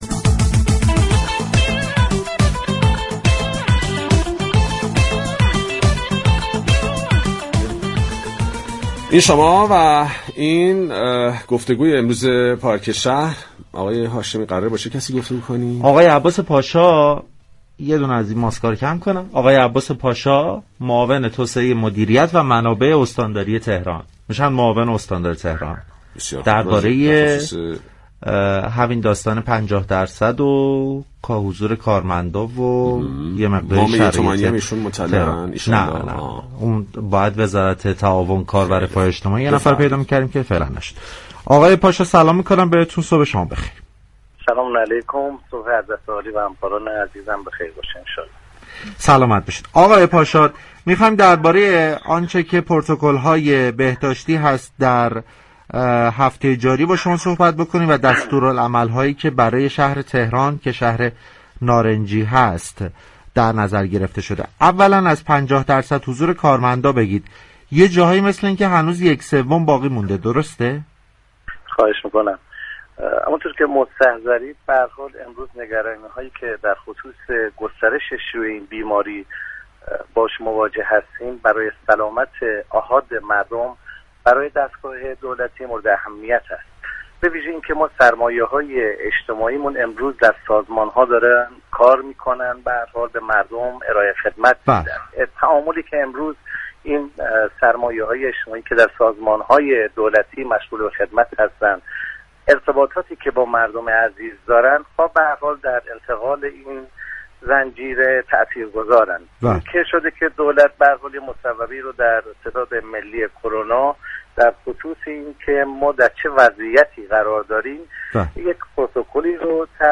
معاون توسعه مدیریت و منابع استان تهران در گفتگو با برنامه پارك شهر رادیو تهران گفت: معاونت امنیتی اصناف و صنعت باید با ابلاغ ستاد كرونا پاسخگوی تعطیلی شركت های خصوصی باشد.